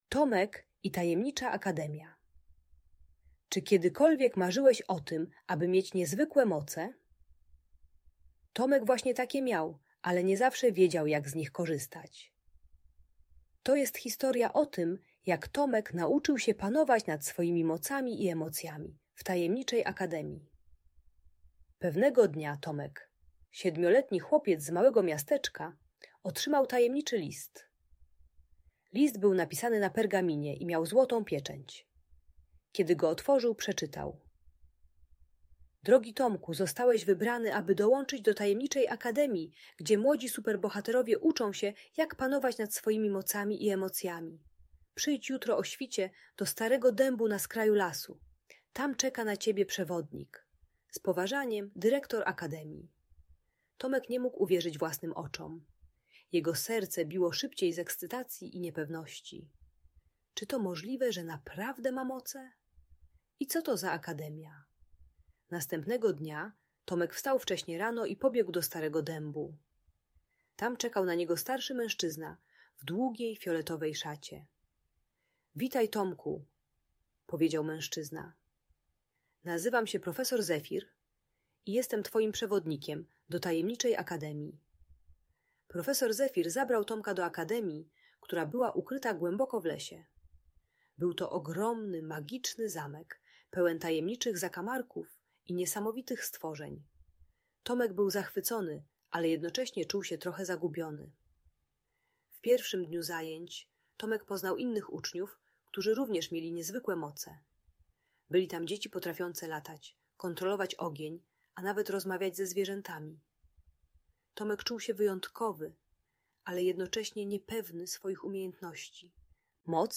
Tomek i Tajemnicza Akademia - Agresja do rodziców | Audiobajka
Uczy technik radzenia sobie ze złością: głębokiego oddychania, medytacji i rozmowy o uczuciach zamiast agresywnych reakcji. Audiobajka o kontrolowaniu emocji i złości dla młodszych uczniów.